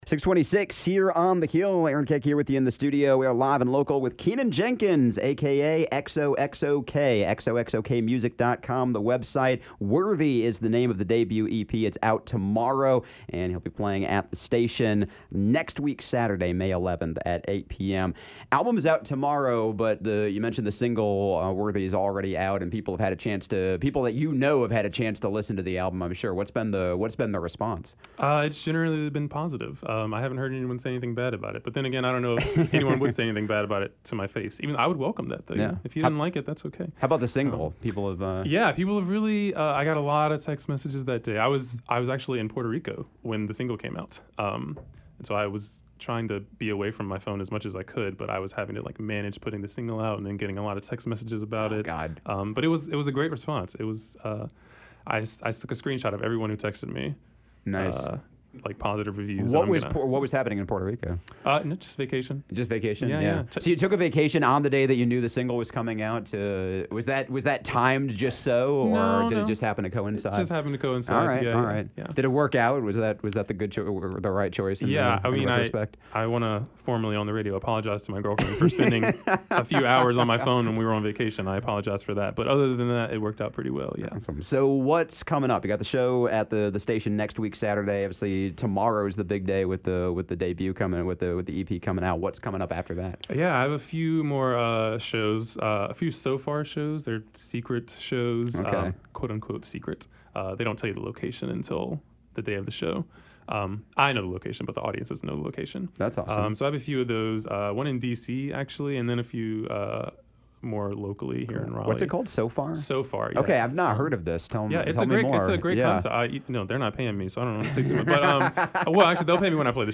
keyboardist